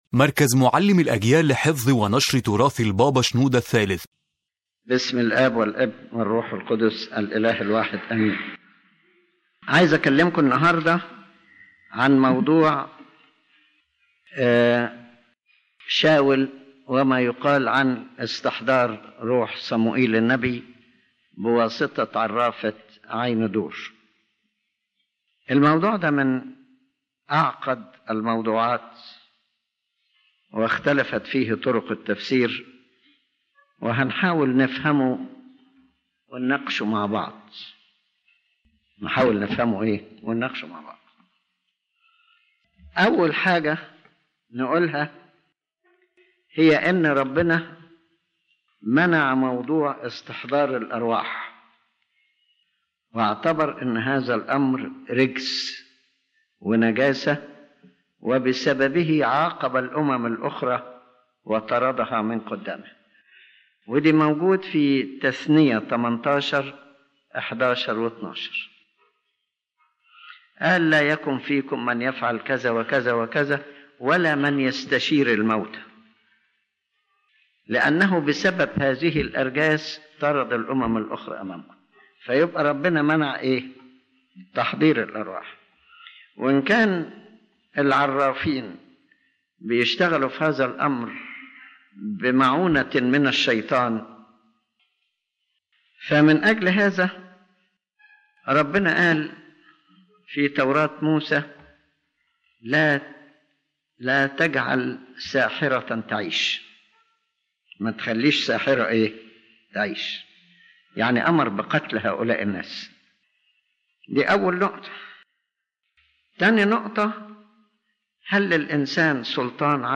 The lecture discusses a difficult issue in interpreting the Holy Bible, which is the incident of King Saul going to the witch of Endor to ask for the summoning of the spirit of the prophet Samuel. Pope Shenouda III examines this incident from a theological and spiritual perspective, trying to understand whether the one who appeared was truly Samuel or another spirit that deceived Saul.